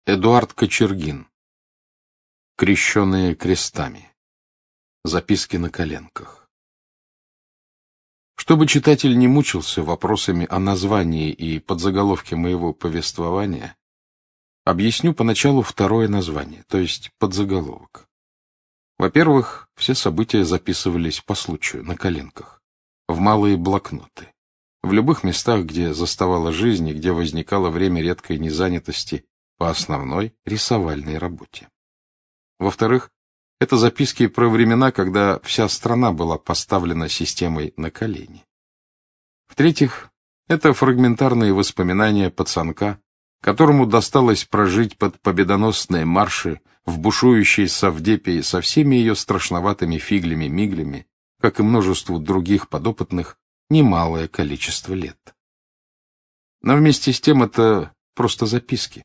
Aудиокнига Крещенные крестами Автор Эдуард Кочергин Читает аудиокнигу Александр Клюквин.